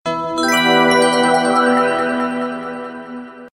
• Качество: 128, Stereo
без слов
сказочные